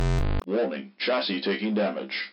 Play, download and share Chasis Damaged original sound button!!!!
chasistakingdamage.mp3